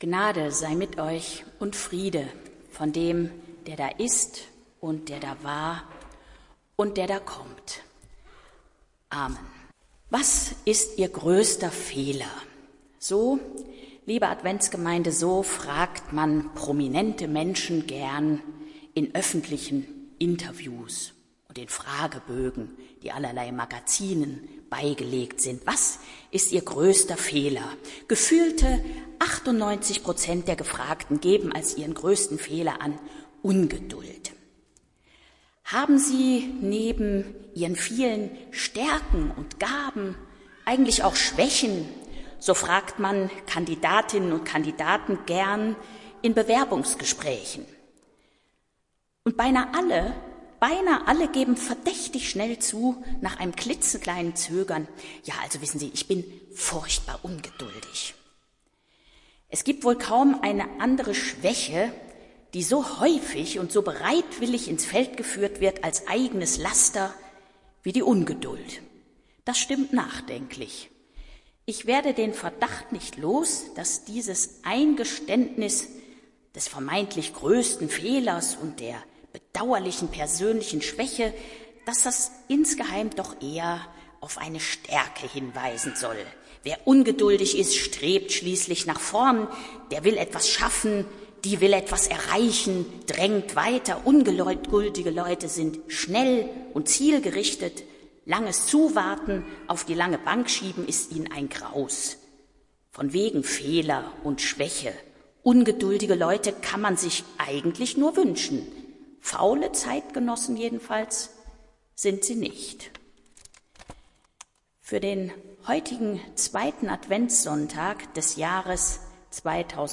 Predigt des Gottesdienstes aus der Zionskirche vom Sonntag, 06.12.2020
Wir haben uns daher in Absprache mit der Zionskirche entschlossen, die Predigten zum Download anzubieten.